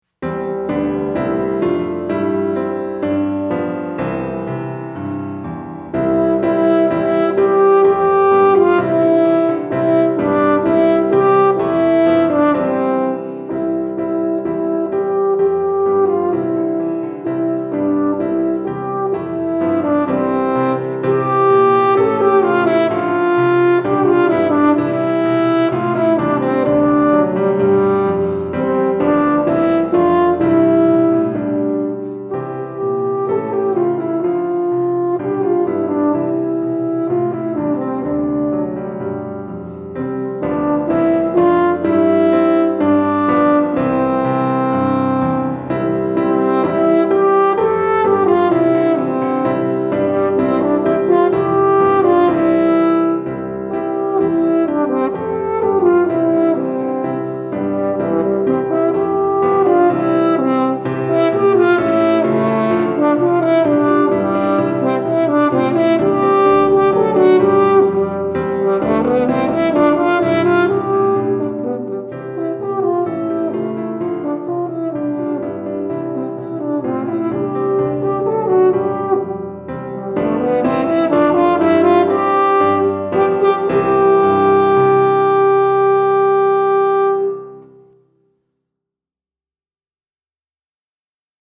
Cor et Piano